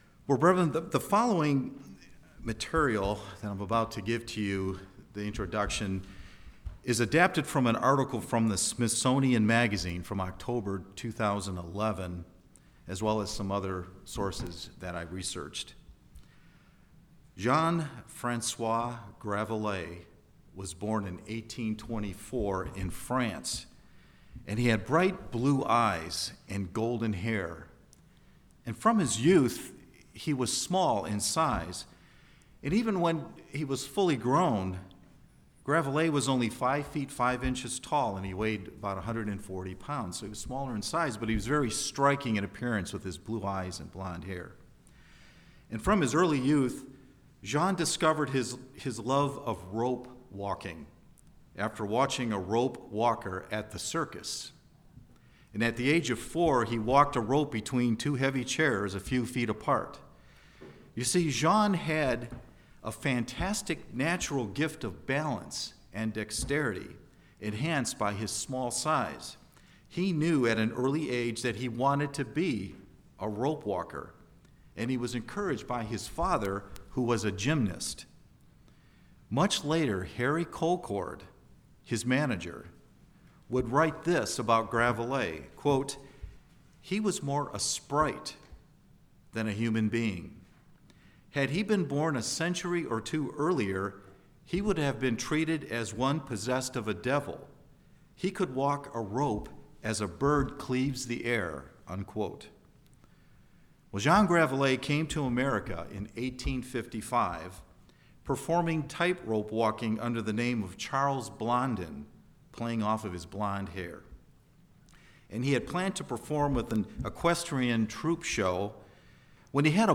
We first must believe that God exists and that the physical is not the only thing that exists in order to truly trust God. This Sermon covers the first four elements in trusting God.